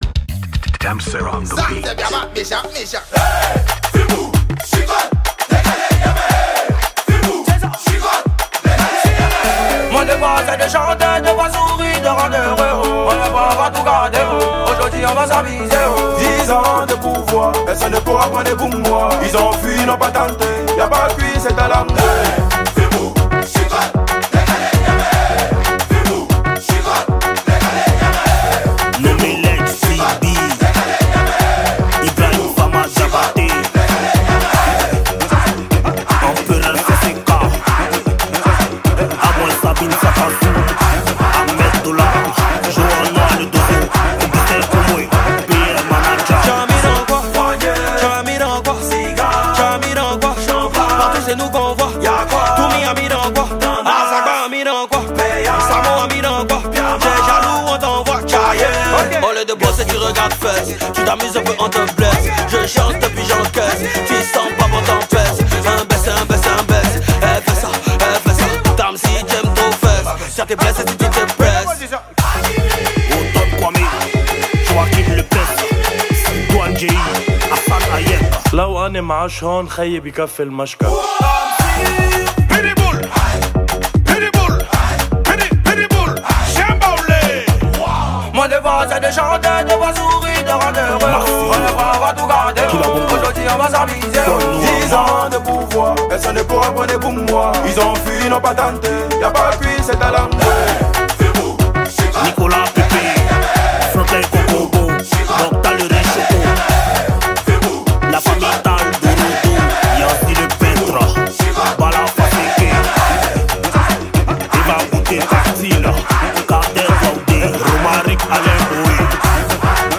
| Afro décalé